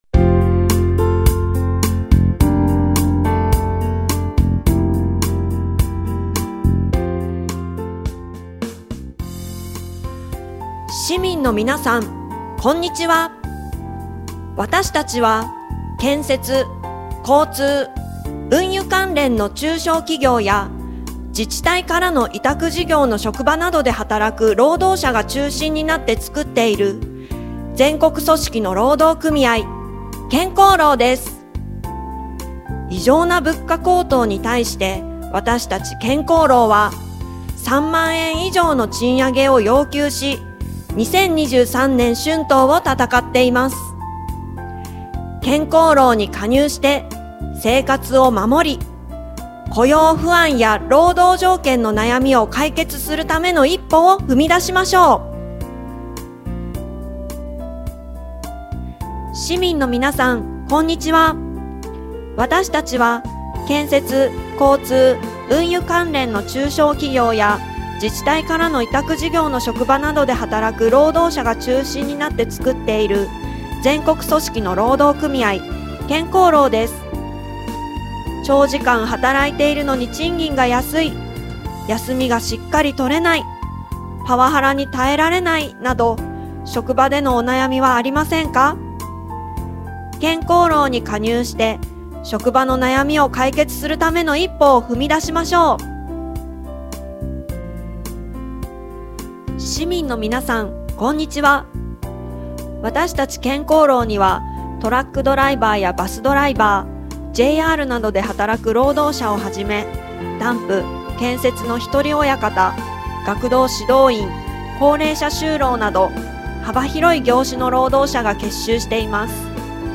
憲法を生かす全国統一署名 ダウンロードする 署名 過労死と職場における差別の根絶を求める国会請願署名 過労死と職場における差別の根絶を求める国会請願署名 ダウンロードする その他宣伝物 2023年春闘版建交労宣伝音源データ 宣伝カーでの流し音源 ダウンロードする 署名 大軍拡、大増税に反対する請願署名 ダウンロードする